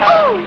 Worms speechbanks
Ooff2.wav